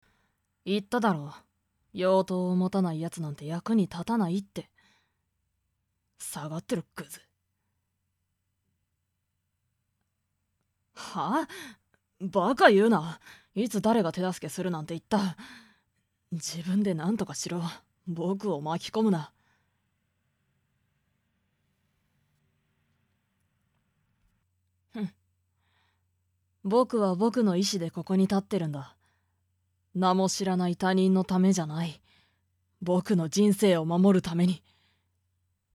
演じていただきました！